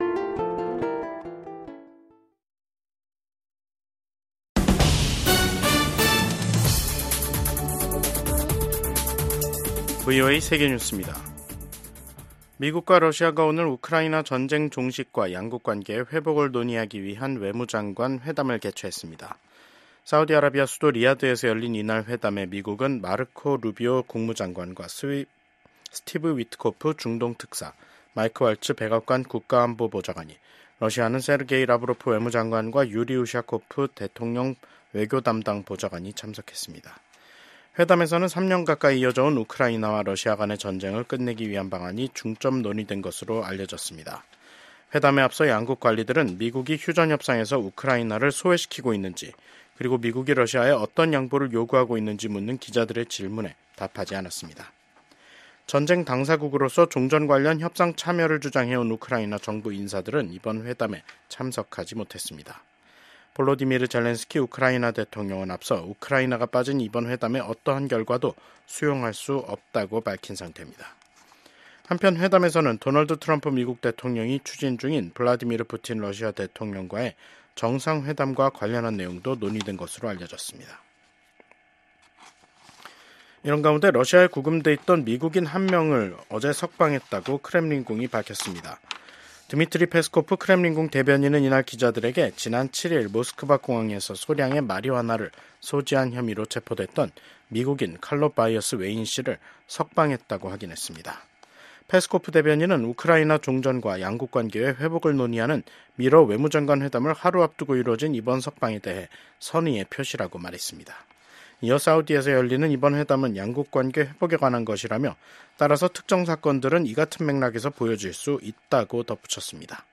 VOA 한국어 간판 뉴스 프로그램 '뉴스 투데이', 2025년 2월 18일 2부 방송입니다. 미국 정부가 북한의 핵·미사일 위협을 비판하며 한국, 일본 등 동맹과 긴밀히 협력하고 있다고 밝혔습니다. 한국이 유엔 안보리 회의에서 러시아에 병력을 파병한 북한을 강하게 규탄했습니다. 북한은 미한일 외교장관들이 북한의 완전한 비핵화를 명시한 공동성명을 발표한 데 대해 반발하는 담화를 냈습니다.